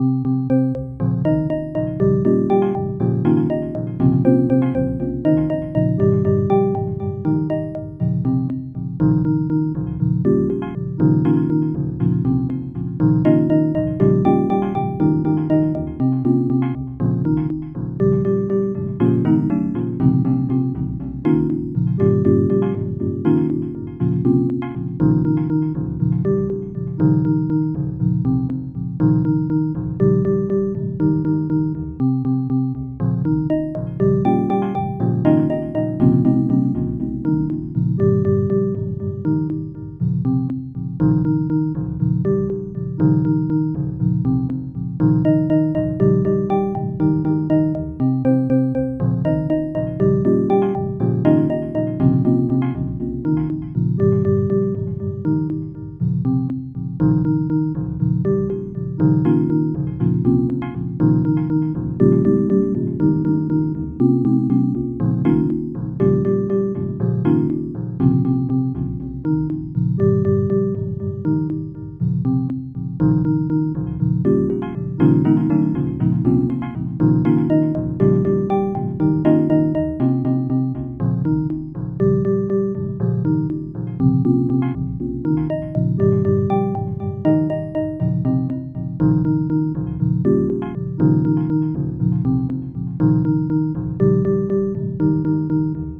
Bucle de electrónica experimental
Música electrónica
repetitivo sintetizador